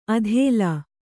♪ adhēla